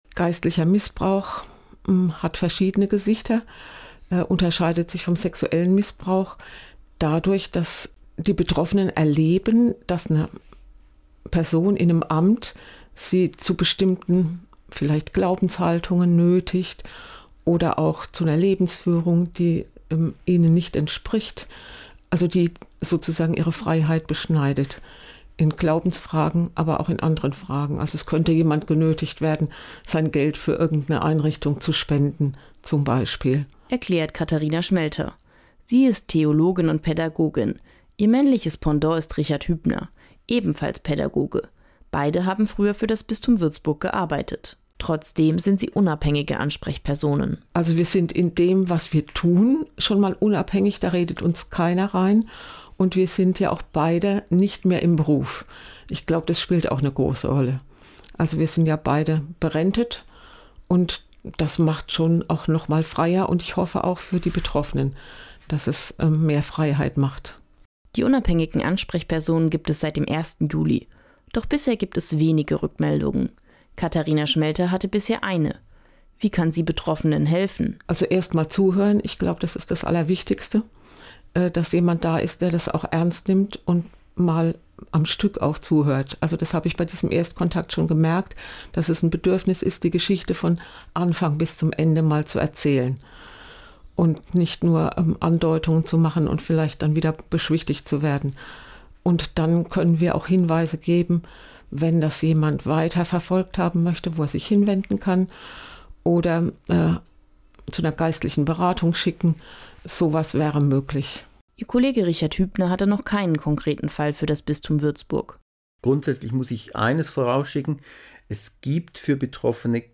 Einen Mann und eine Frau.